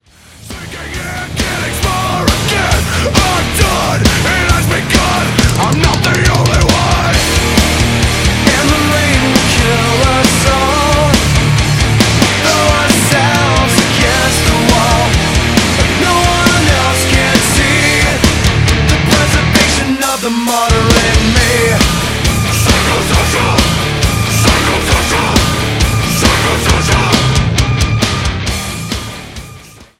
ВСЯФ ЗЛОСТЬ И НЕНАВИСТЬ...